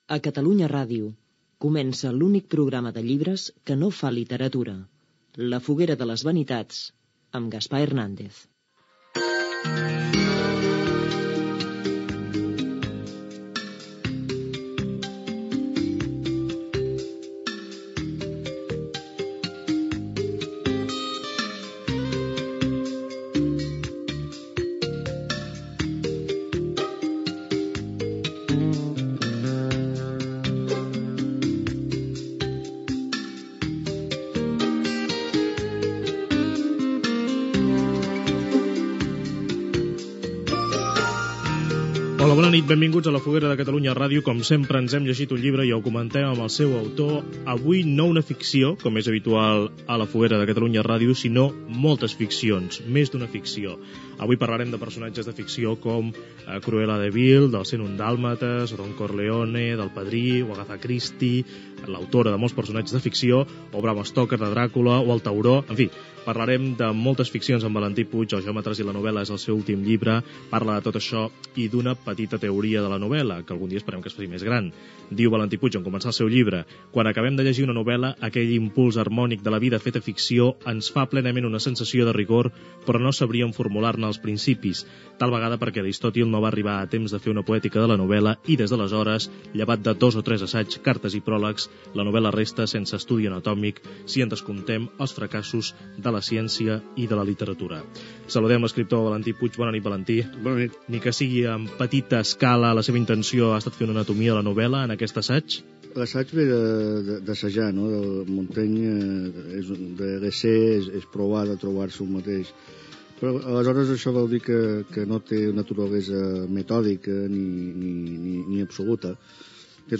Identificació i presentació del programa. Entrevista l'escriptor mallorquí Valentí Puig sobre l'assaig que ha escrit sobre la novel·la.